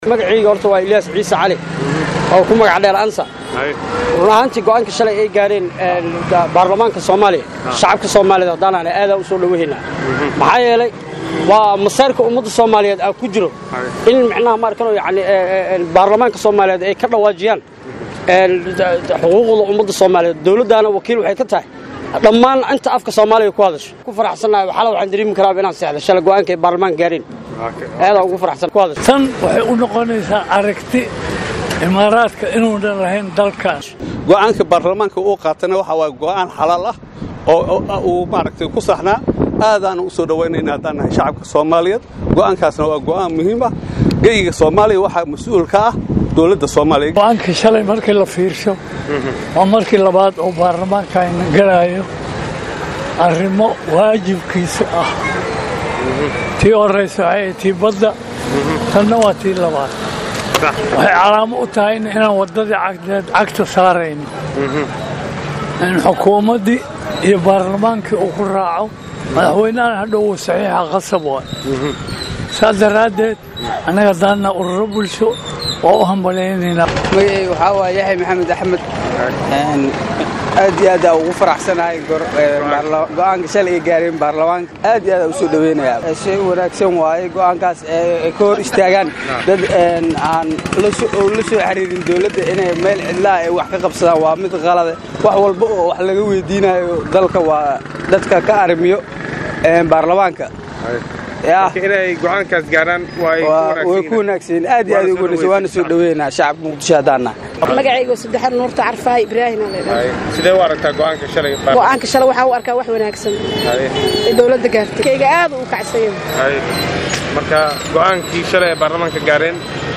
Ugu horey waxaa maanta warbaahinta qaranka u suurta gashay iney aarahdooda uruuriso qeybaha kala duwan ee bulshada ku dhaqan caasimada muqdisho si ay u ogaato aragtidooda ay ka aaminsanyihiin arinta hishiiska dekadda barabara.
Halkaan ka dhageyso codaadka shacaabka soomaaliyeed ee argtida ka dhibtay go,aankii barlaamaanka dalka u gartay.